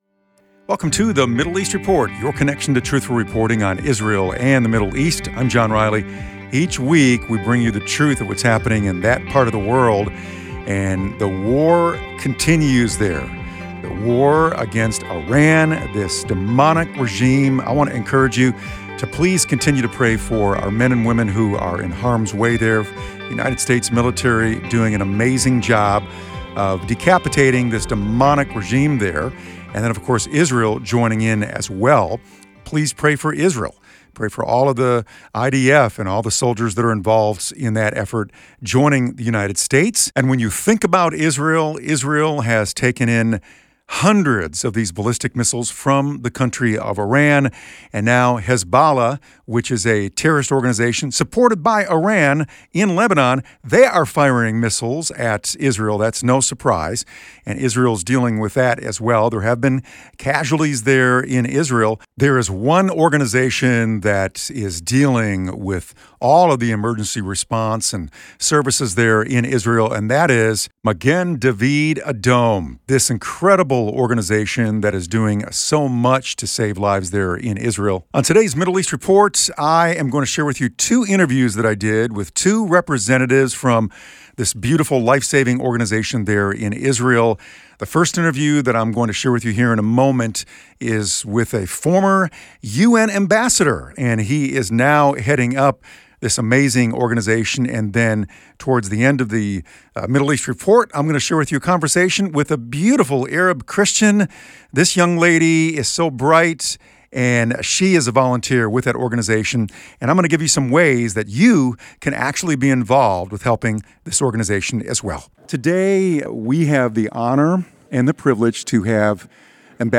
Ambassador Gilad Erdan discusses his experience at the United Nations, explaining how its changed political makeup, low bar for membership, powerful voting blocs of non-democracies, and equal voting power for all states lead to moral distortions and votes against Israel. He advocates for defunding the UN.